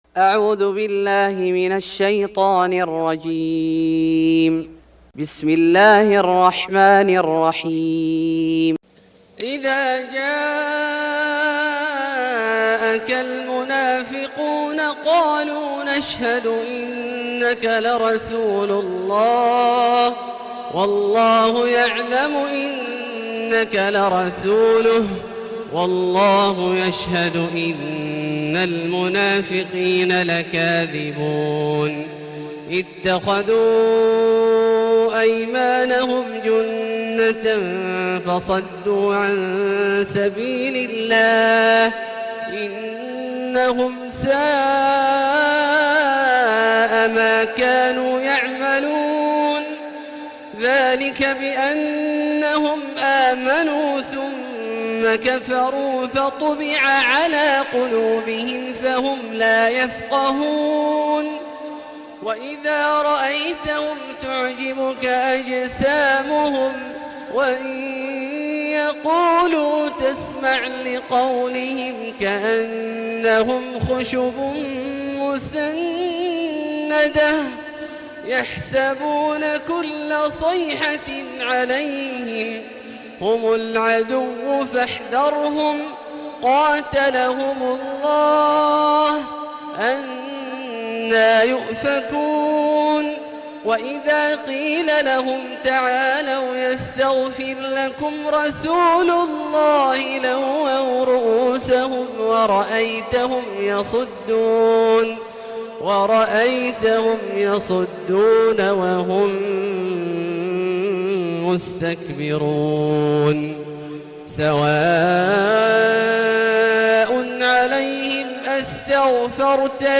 سورة المنافقون Surat Al-Munafiqun > مصحف الشيخ عبدالله الجهني من الحرم المكي > المصحف - تلاوات الحرمين